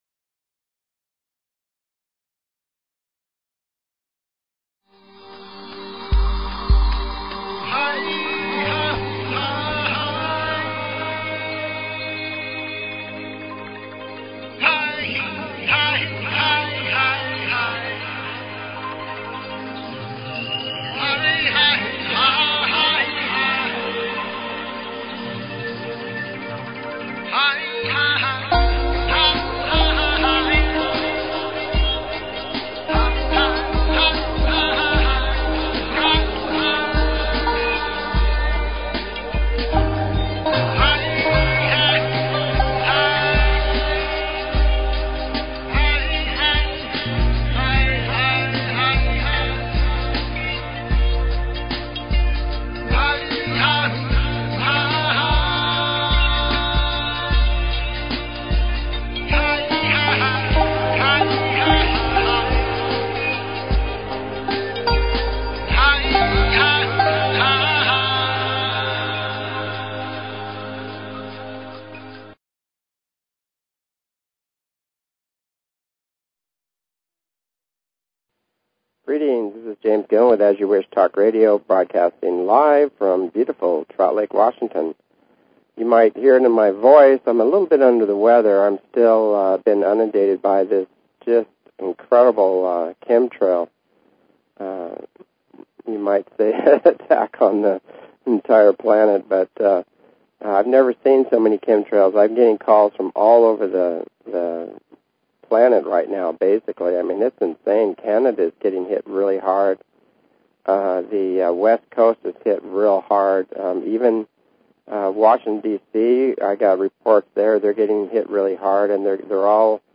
Talk Show Episode, Audio Podcast, As_You_Wish_Talk_Radio and Courtesy of BBS Radio on , show guests , about , categorized as
Skywatch at the ECETI Ranch, taking it to the field and interviewing eyewitnesses